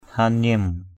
/ha-niim/